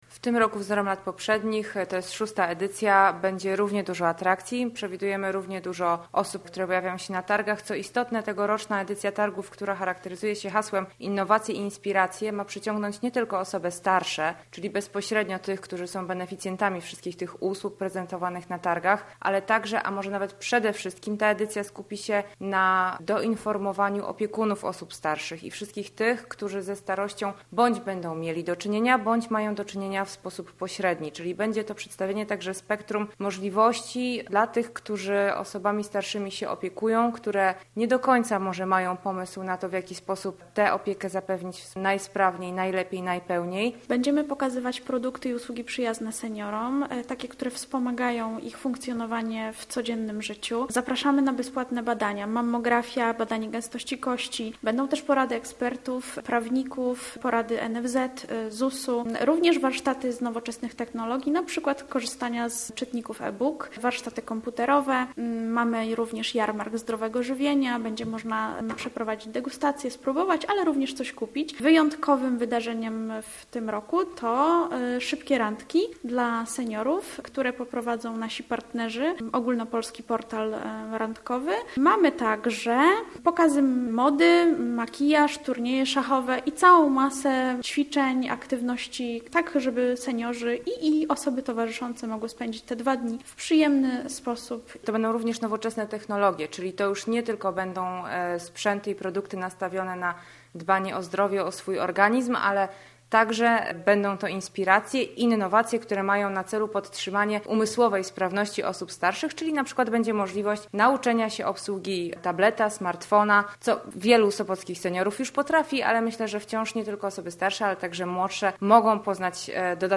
rozmawiał na ten temat z wiceprezydent Sopotu